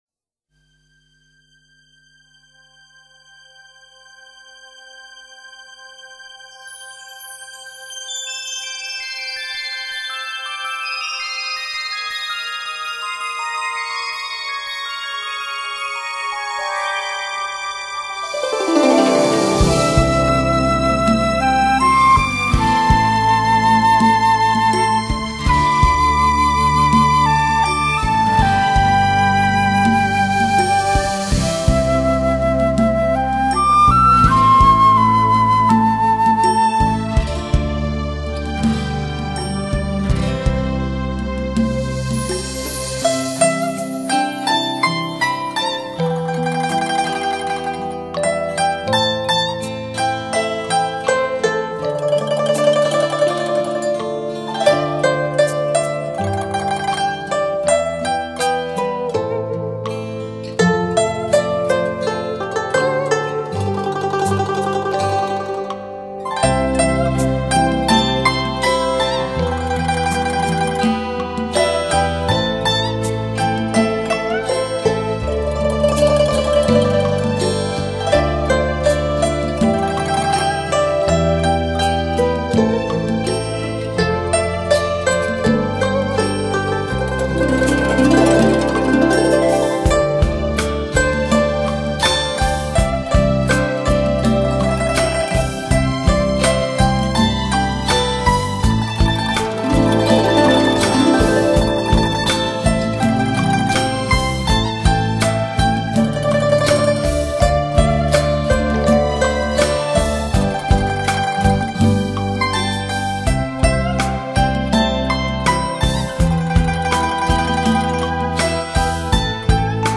古筝
二胡
竹笛
长笛
中提琴
吉它
流淌的古乐新曲：用中华传统乐器与现代配器相结合演绎粤语流行曲。
古筝弹奏出轻柔舒曼、悠扬乐韵、东方味道，如高山流水、伯牙遇知音、乐琴忘返。